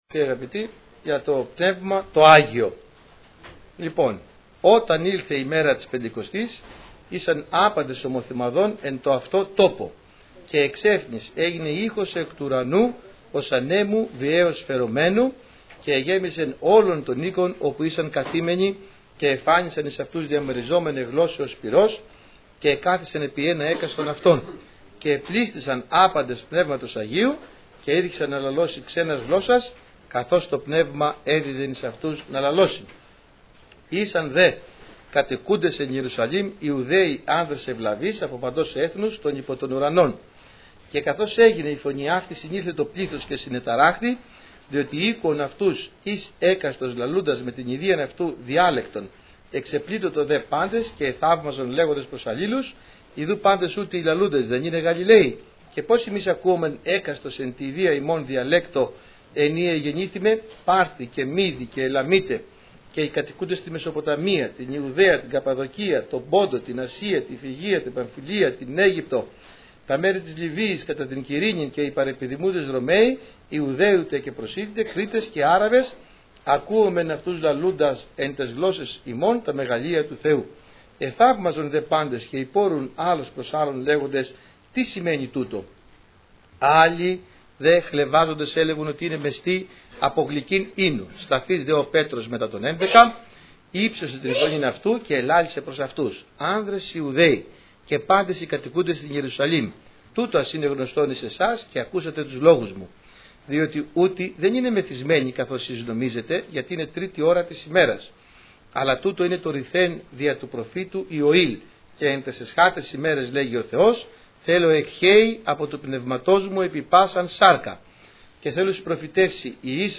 Σειρά: Κηρύγματα